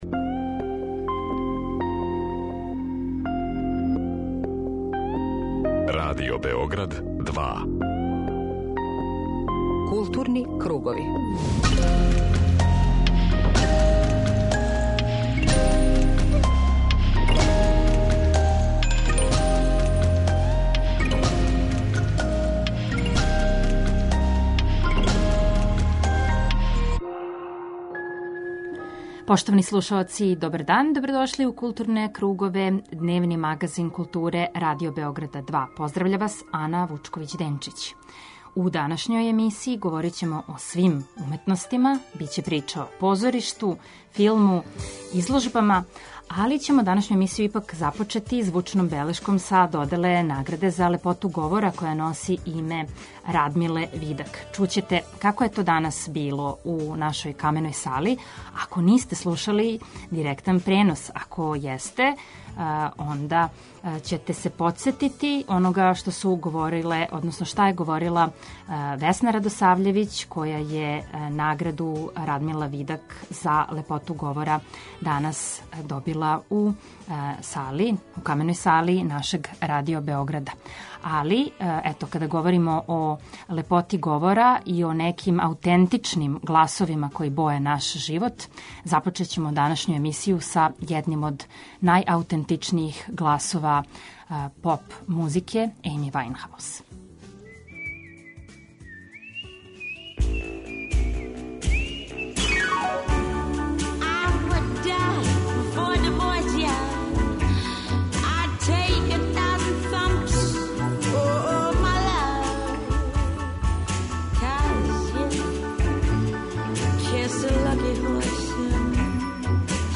Магазин културе Радио Београда 2
Данашњу емисију започећемо звучном белешком са доделе Награде за лепоту говора, која носи име Радмиле Видак.